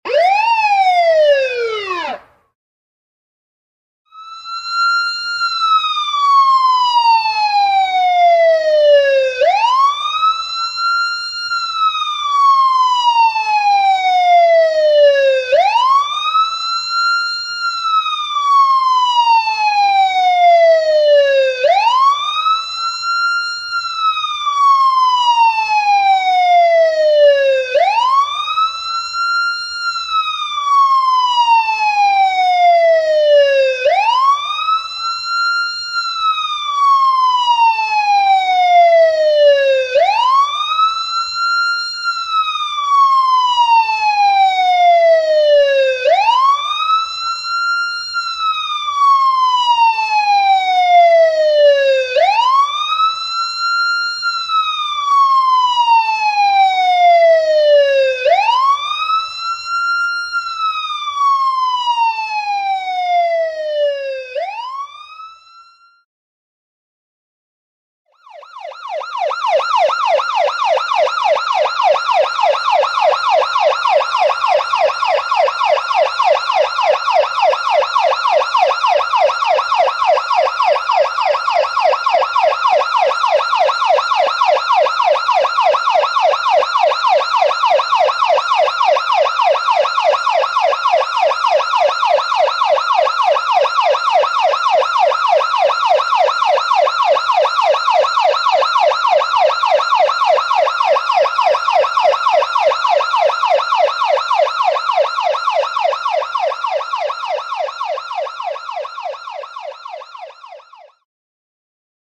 🚑🔊 Sirens blaring, racing against